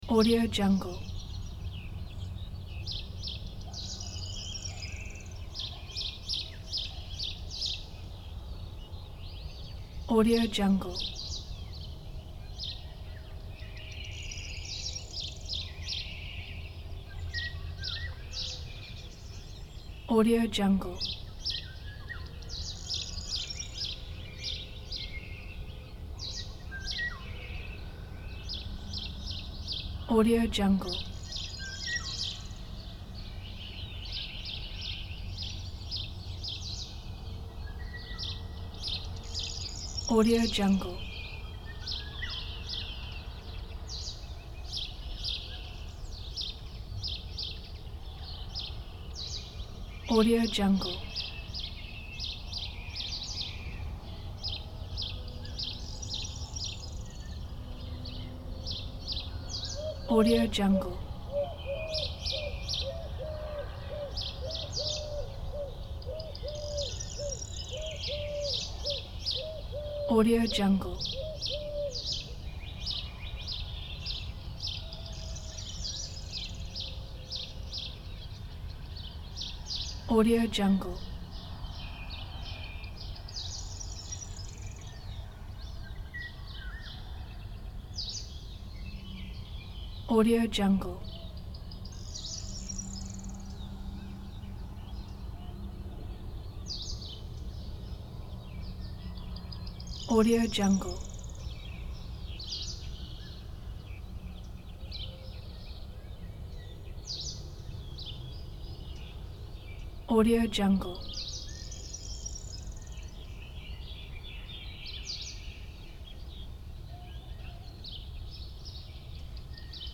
دانلود افکت صدای حومه شهر در تابستان با صدای پرندگان
دانلود افکت صوتی شهری
• ایجاد جو آرامش‌بخش و طبیعی: صدای پرندگان، وزش نسیم ملایم، صدای حشرات و سایر صداهای محیطی حومه شهر در تابستان، حس آرامش و طبیعت را به بیننده منتقل می‌کند و می‌تواند برای ایجاد جوهای رمانتیک، آرامش‌بخش یا حتی ماجراجویانه در ویدیوهای شما استفاده شود.
16-Bit Stereo, 44.1 kHz